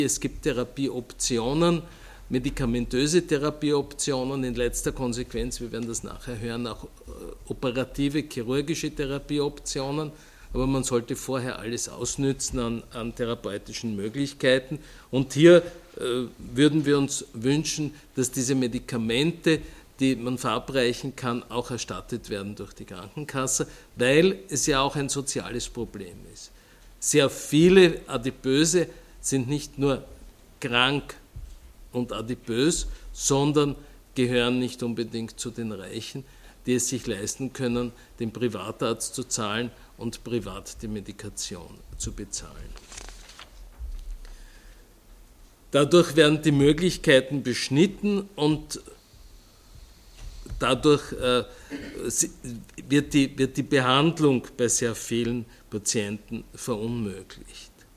.mp3 O-Ton Dateien der Pressekonferenz vom 21.06.2022: